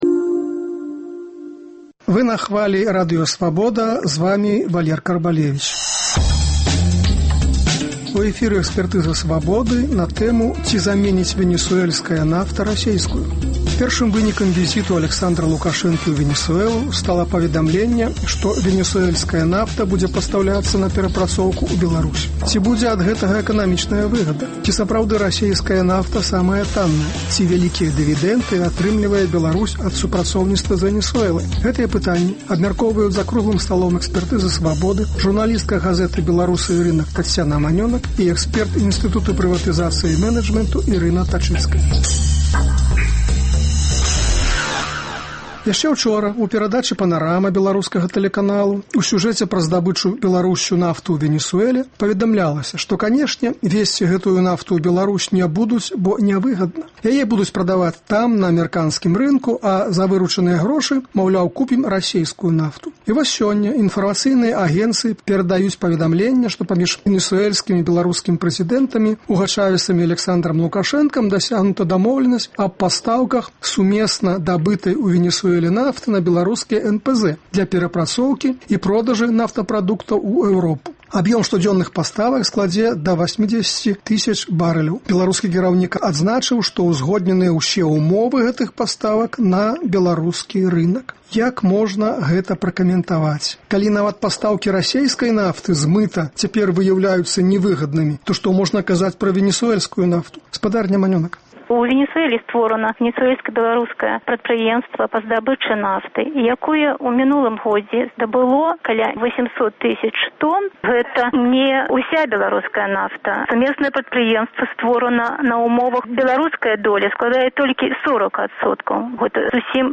Гэтыя пытаньні абмяркоўваюць за круглым сталом "Экспэртызы "Свабоды”